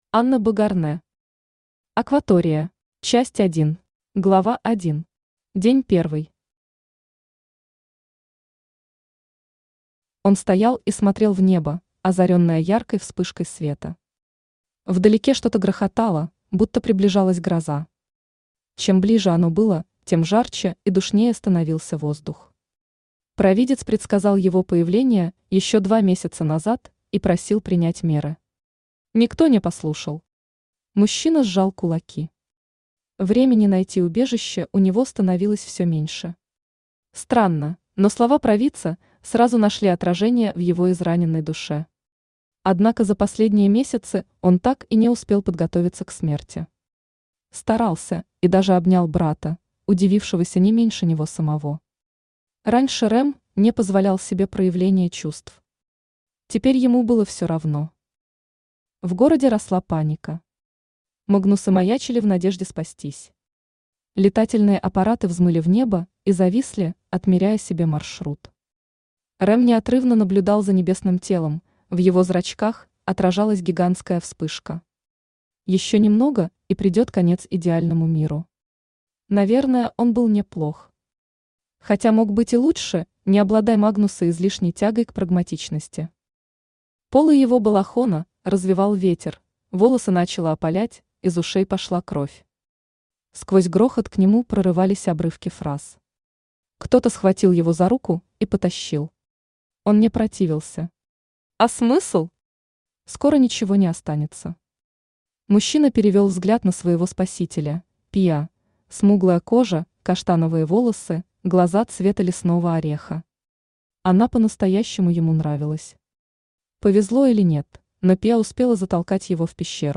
Аудиокнига Акватория | Библиотека аудиокниг
Aудиокнига Акватория Автор Анна Богарнэ Читает аудиокнигу Авточтец ЛитРес.